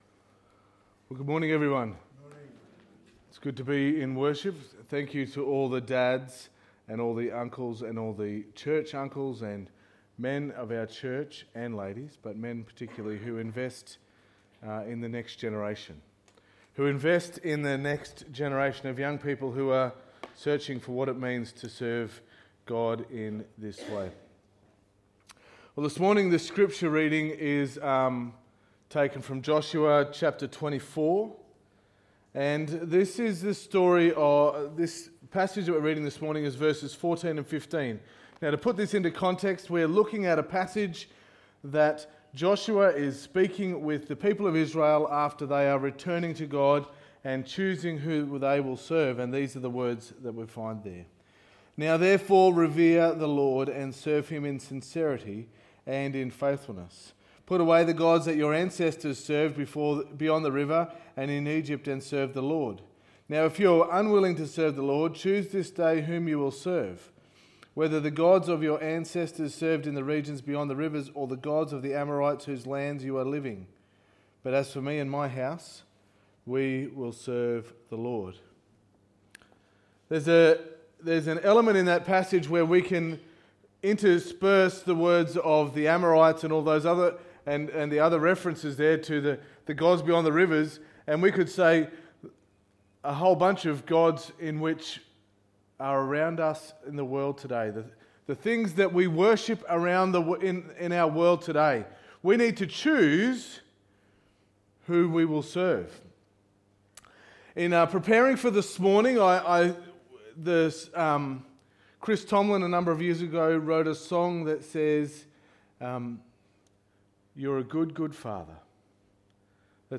Sermon 01.09.2019